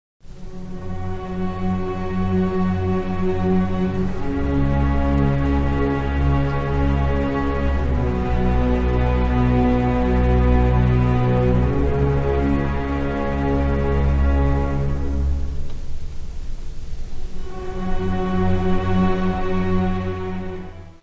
baixo
soprano
orgão
Music Category/Genre:  Classical Music